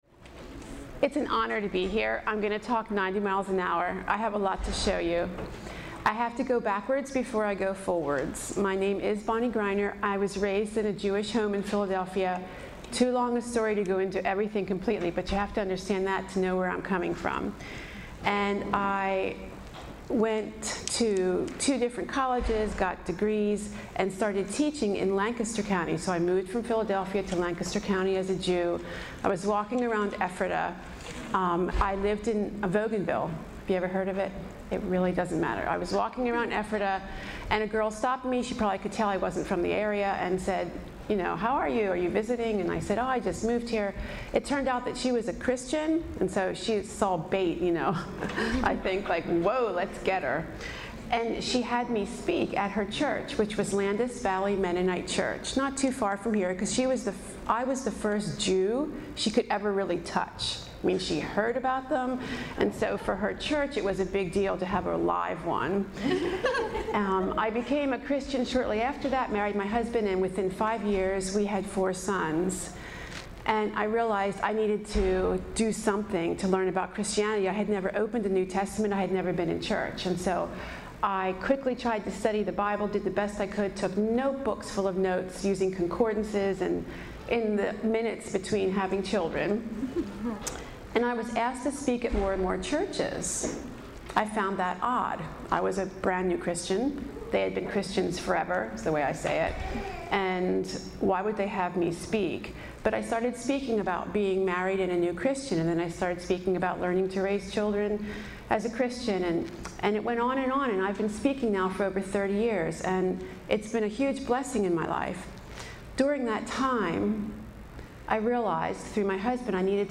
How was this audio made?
On February 1st, 2020, home educators gathered in Manheim, PA for the 2020 Midwinter Conference.